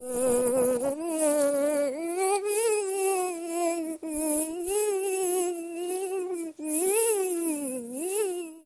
Звук песенки пчелки и жужжания из мультфильма